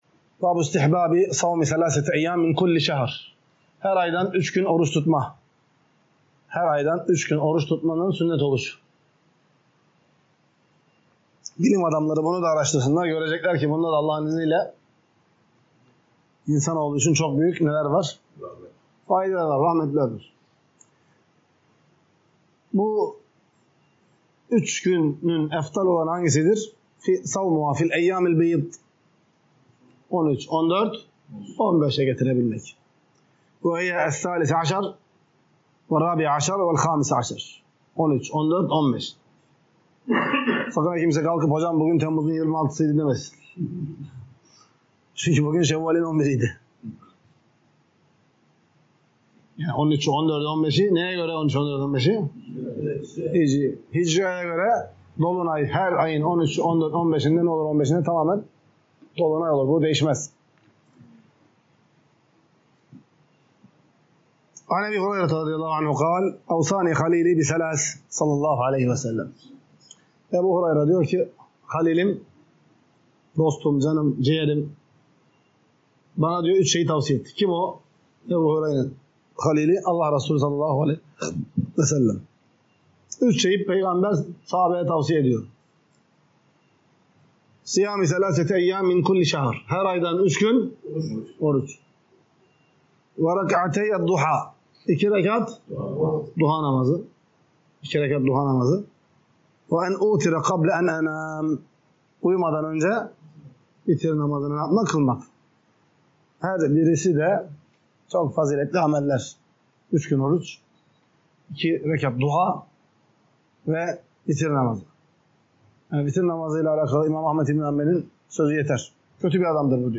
Ders - 51. BÖLÜM | HER AYDAN ÜÇ GÜN ORUÇ TUTMANIN MÜSTEHAB OLDUĞU